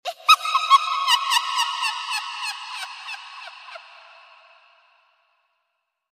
Ominous Laughter Of A Witch Sound Effects Free Download